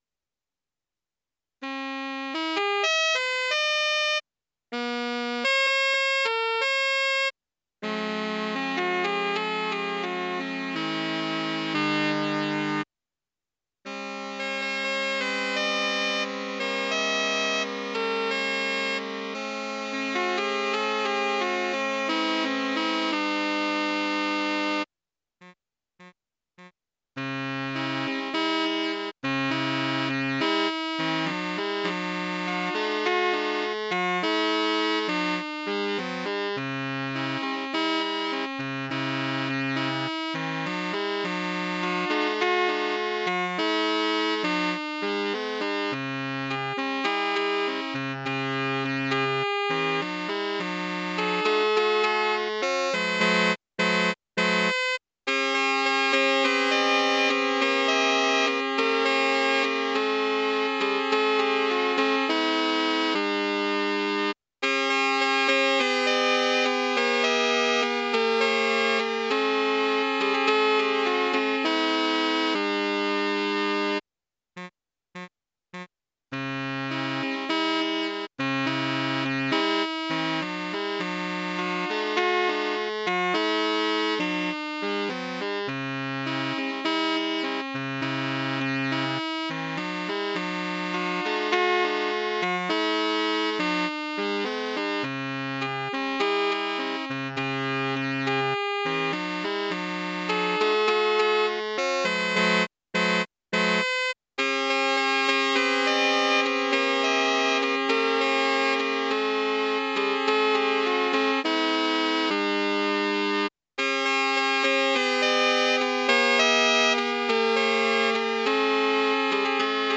Ensemble de Saxos
alsolsaxos.mp3